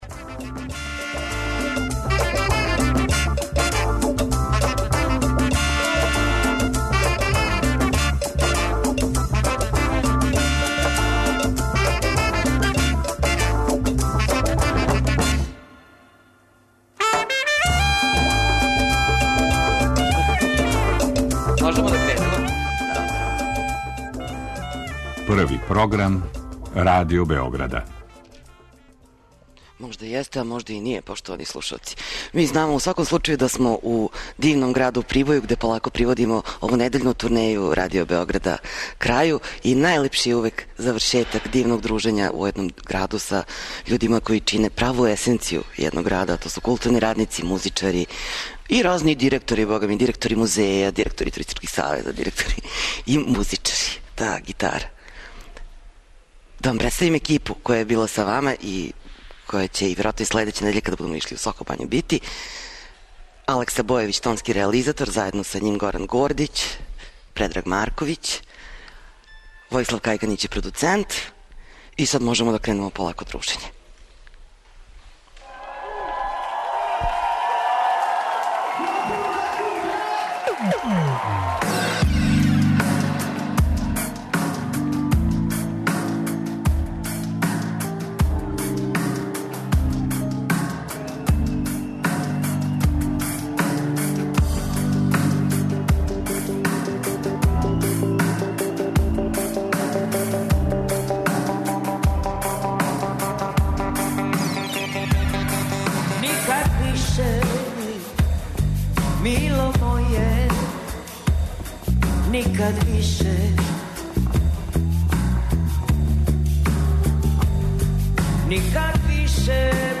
Емисију емитујемо уживо из Прибоја, представићемо вам културни живот овога града.